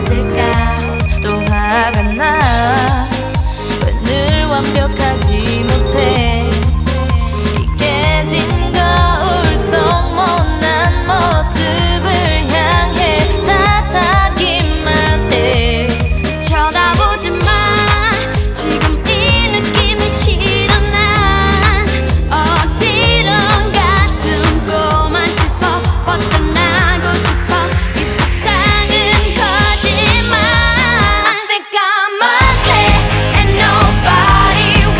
Жанр: Танцевальные / Поп / K-pop